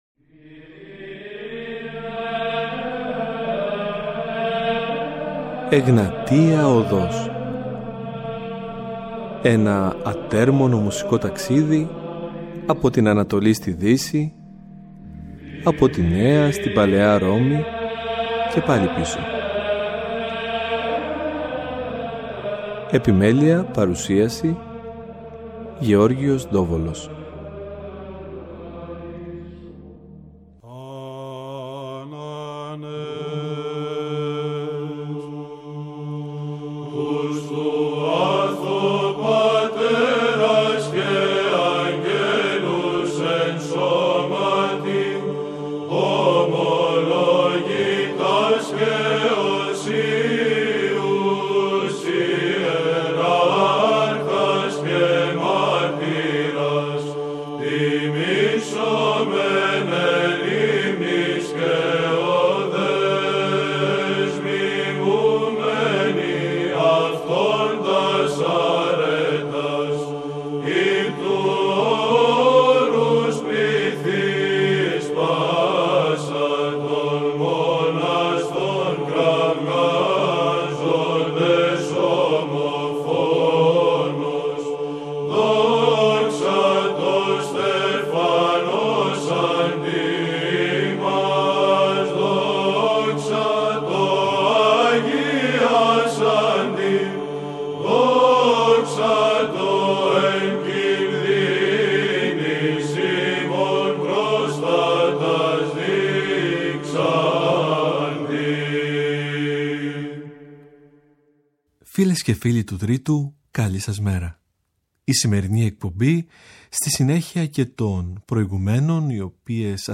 αμφότεροι παραδοσιακοί ερμηνευτές
Βυζαντινη Μουσικη
Ορθοδοξη Εκκλησιαστικη Μουσικη